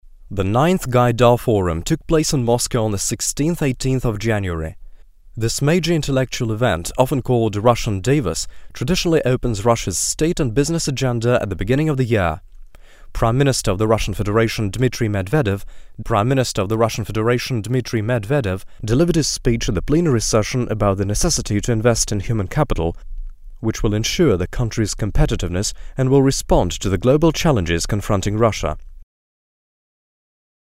avosМужской голос на английском языке (043)